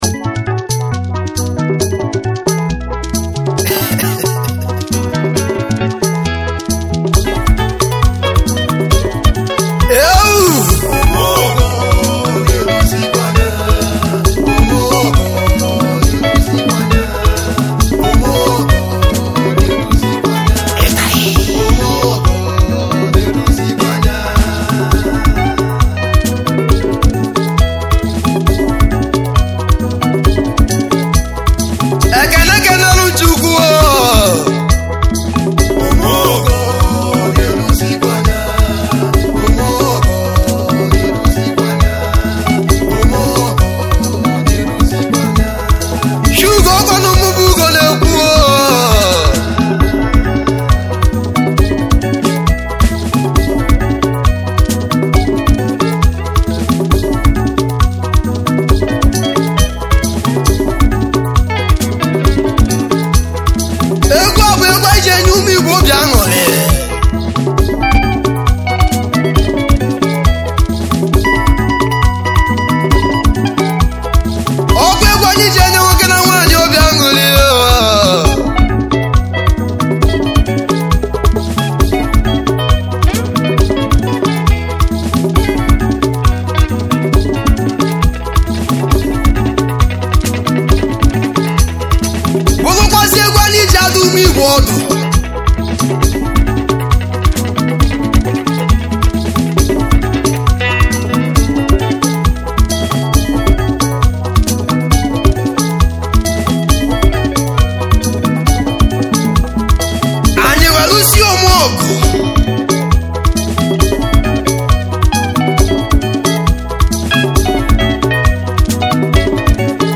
igbo highlife musician's
highlife music band